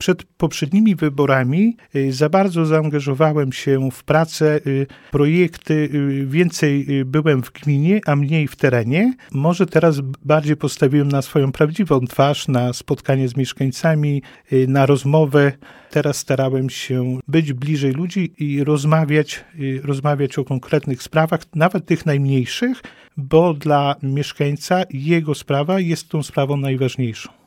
Samorządowiec mówił na naszej antenie, że wyciągnął wnioski z poprzedniej nieudanej kampanii i tym razem postawił na spotkania z mieszkańcami.